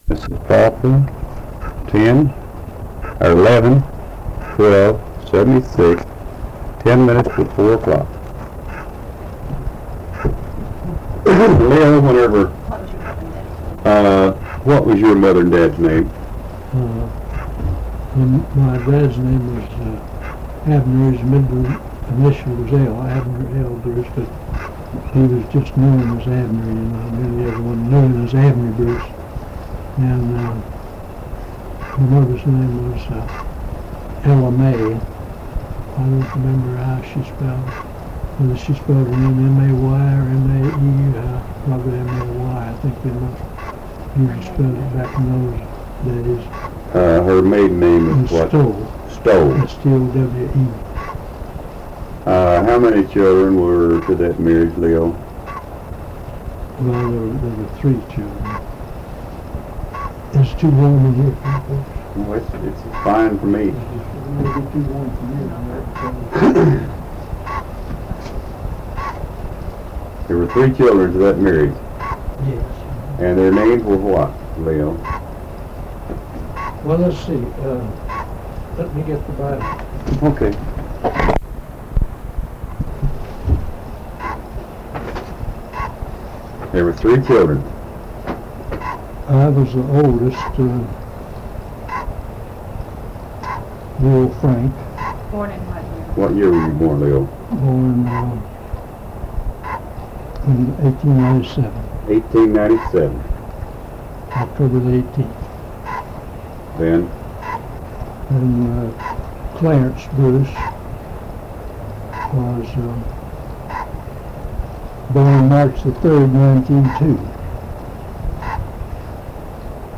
Oral History Archive | Pinehill Community